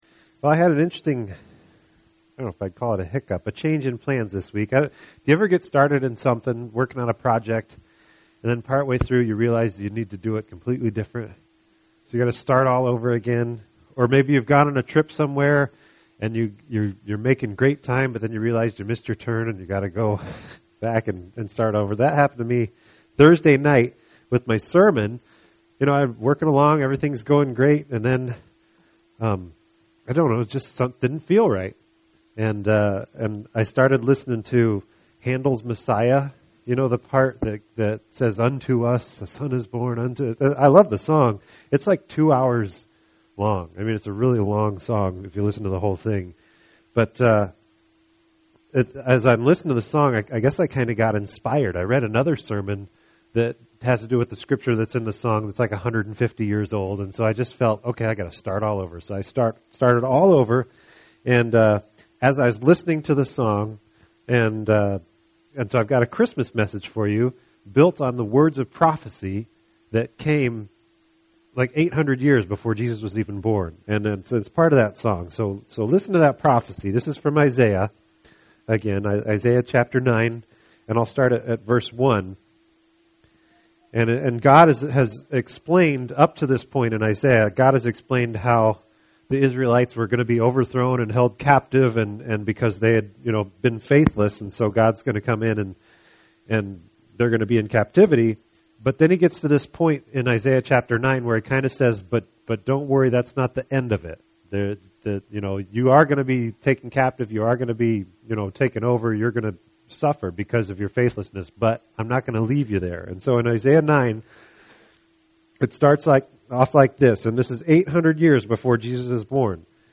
This entry was posted on Monday, November 5th, 2012 at 1:23 am and is filed under Sermons.